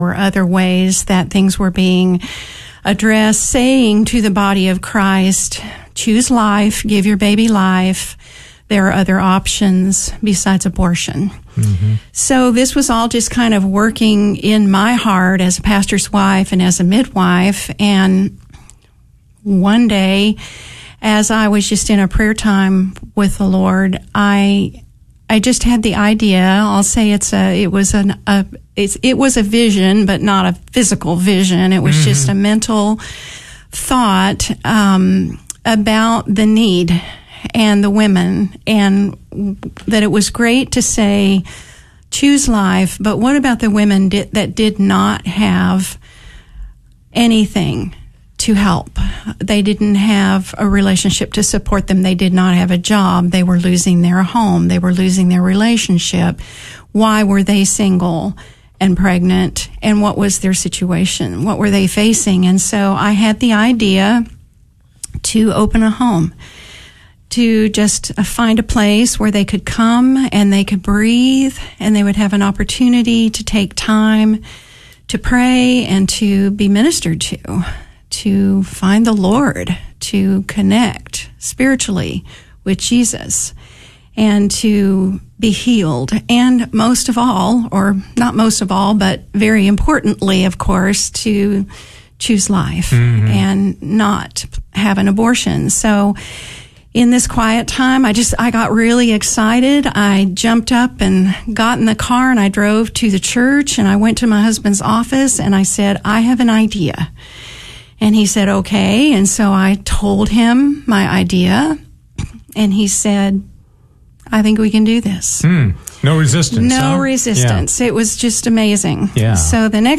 KATH Interview of the Week - Saturday July 05, 2025